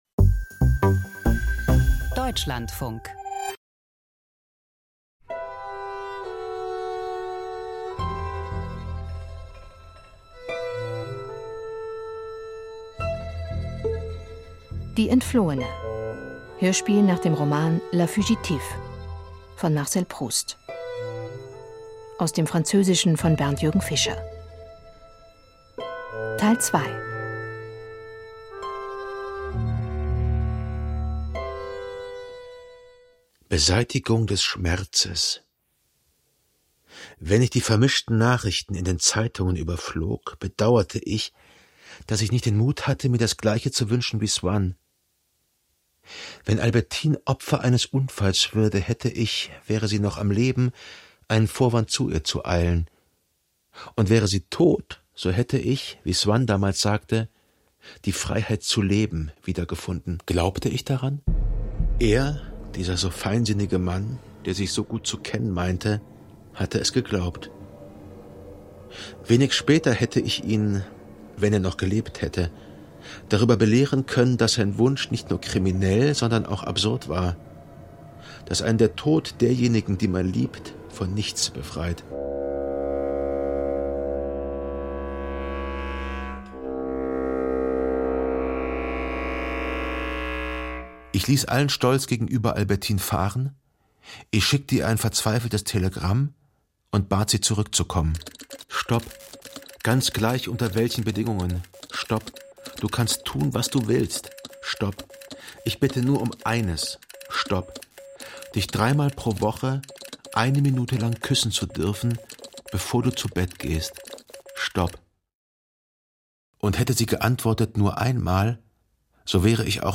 Hörspiel nach Marcel Proust - Die Entflohene (2/4)